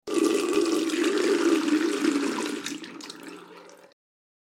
دانلود آهنگ آب 54 از افکت صوتی طبیعت و محیط
جلوه های صوتی
دانلود صدای آب 54 از ساعد نیوز با لینک مستقیم و کیفیت بالا